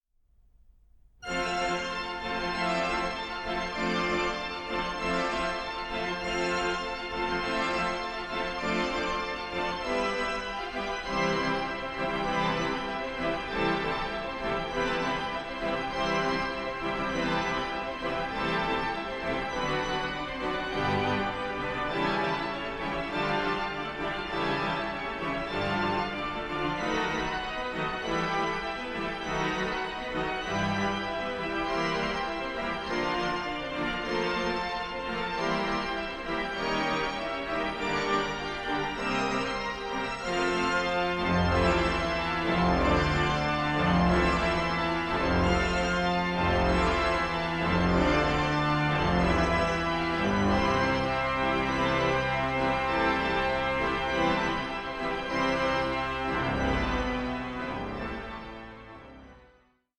offers a vibrant conclusion to this programme
The Geneva Victoria Hall organ